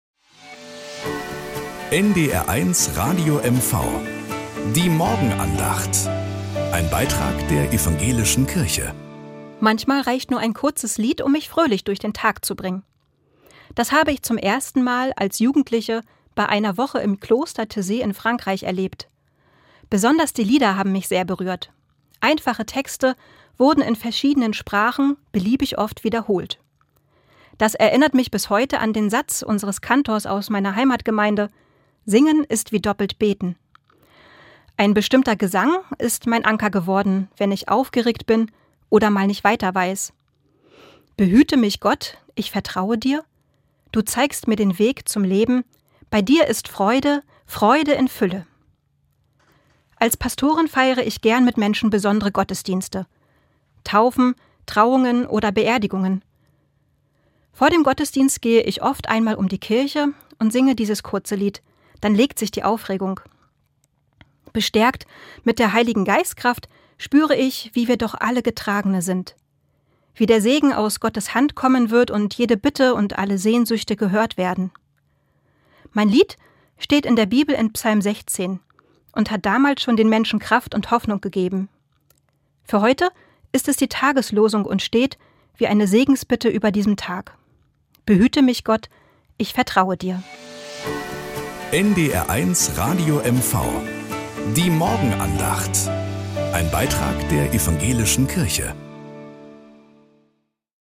Morgenandacht bei NDR 1 Radio MV
Um 6:20 Uhr gibt es in der Sendung "Der Frühstücksclub" eine